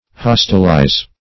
Search Result for " hostilize" : The Collaborative International Dictionary of English v.0.48: Hostilize \Hos"til*ize\, v. t. To make hostile; to cause to become an enemy.